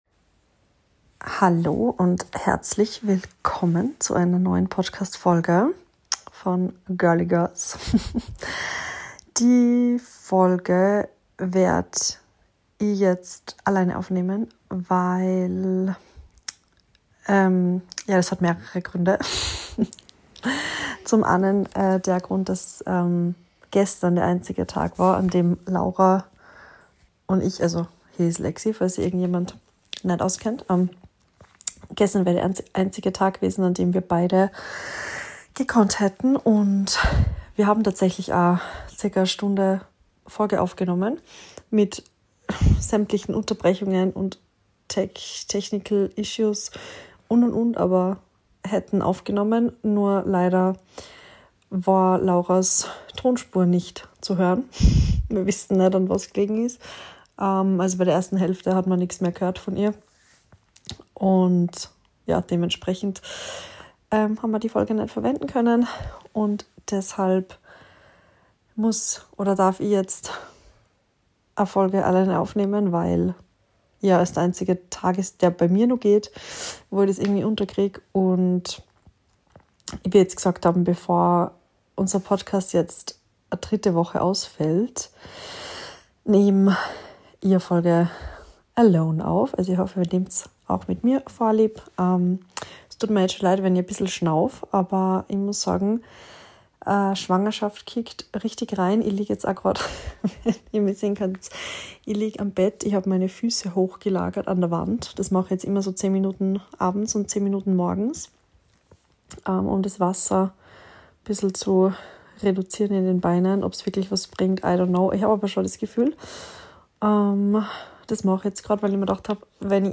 In dieser Solo-Folge nehm ich euch mit in ein ehrliches Urlaubs-Update – Spoiler: All-Inclusive-Familyresorts stehen erstmal nicht mehr auf der Liste!